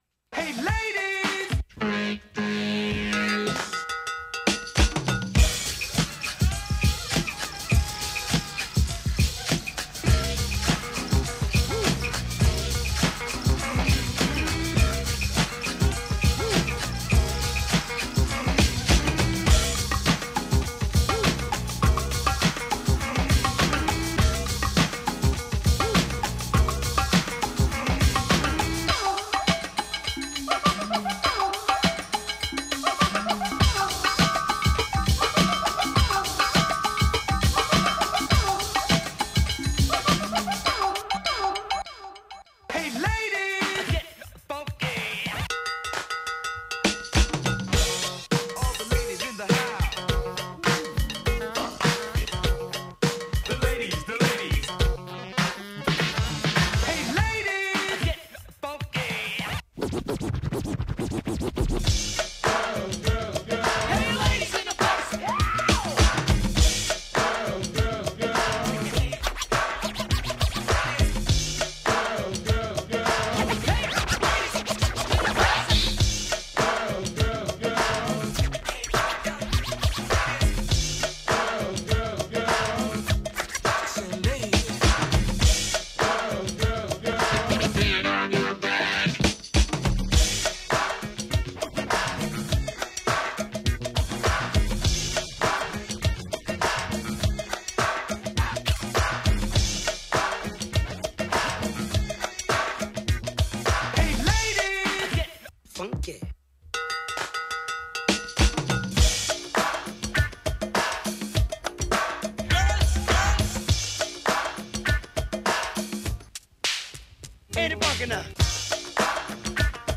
as rehashed instrumentals of the record’s a-side
party-jams